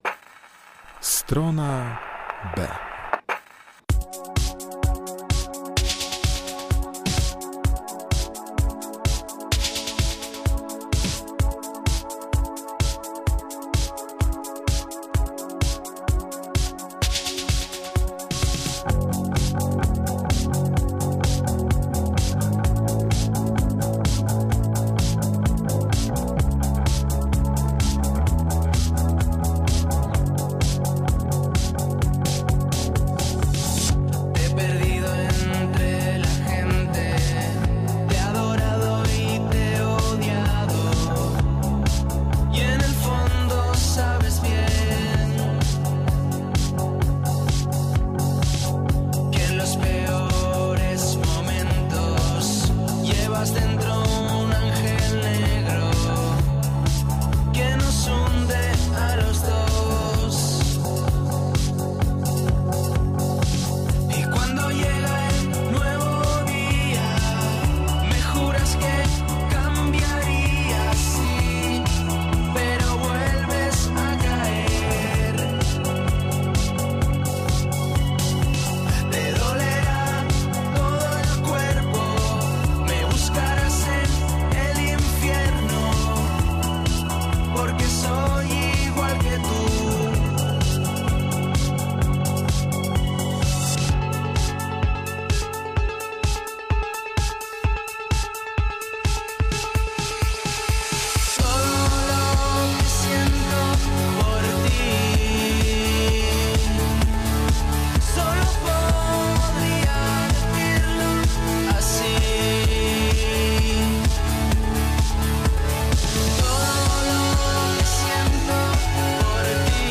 W Stronie B nie zabraknie elektronicznych dźwięków.